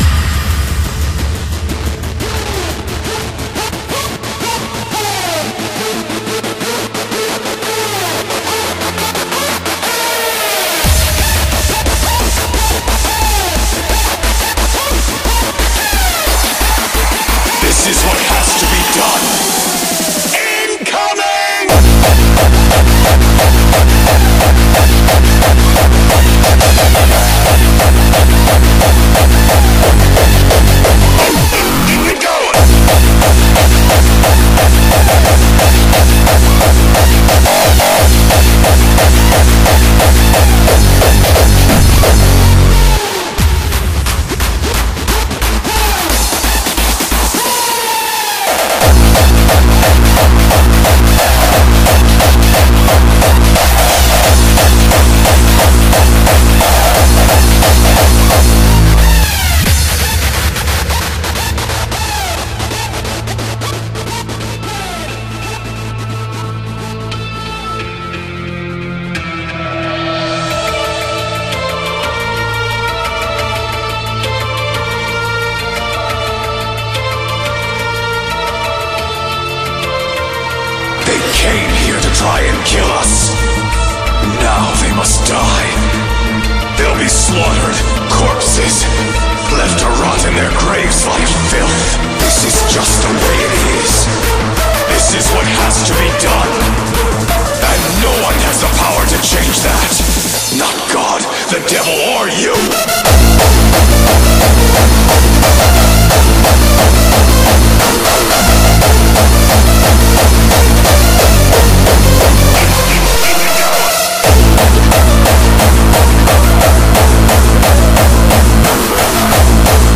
BPM177-354
Audio QualityPerfect (High Quality)
Comments[INDUSTRIAL HARDCORE]